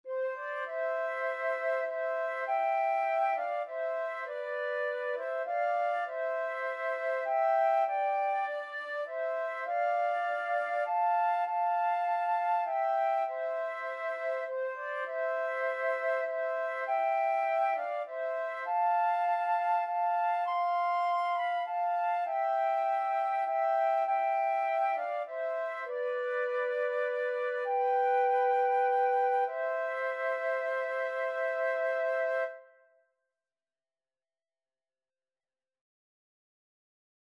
Flute 1Flute 2
6/4 (View more 6/4 Music)
Classical (View more Classical Flute Duet Music)